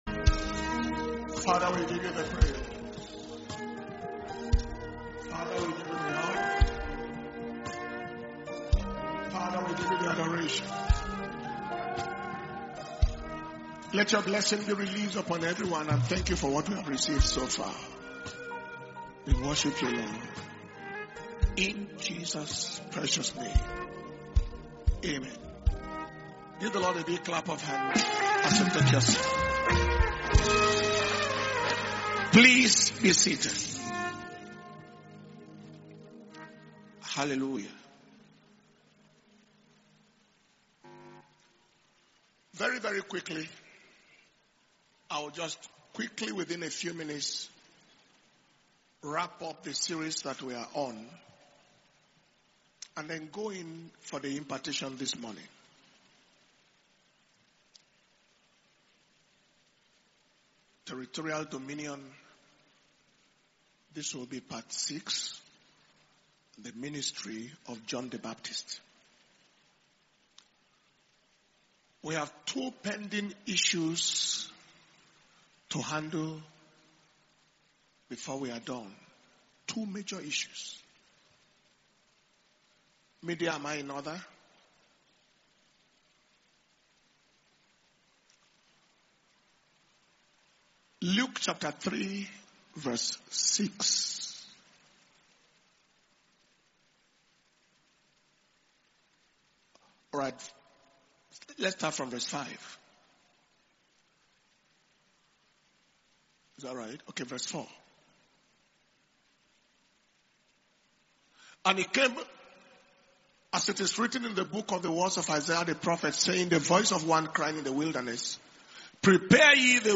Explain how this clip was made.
Dunamis International Ministers’ Flaming Fire Conference (IMFFC 2025) August 2025 – Day 4 Morning